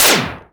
Power Laser Guns Demo
LaserGun_46.wav